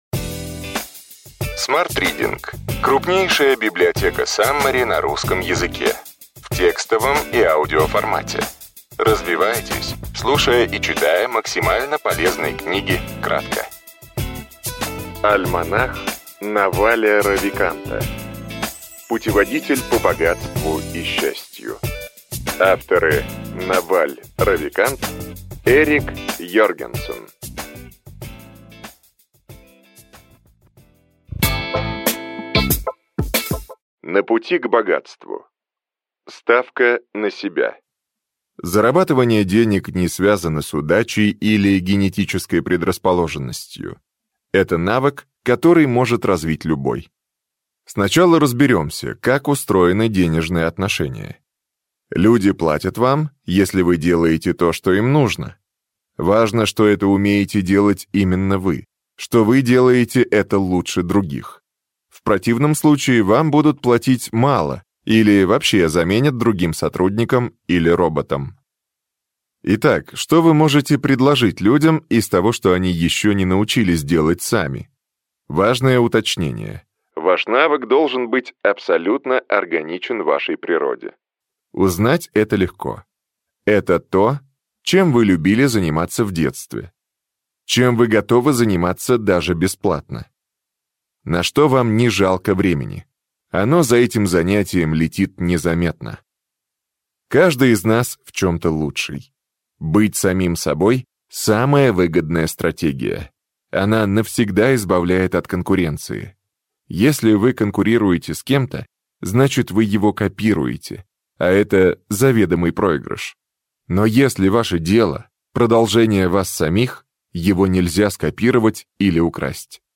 Аудиокнига Ключевые идеи книги: Альманах Наваля Равиканта: путеводитель по богатству и счастью.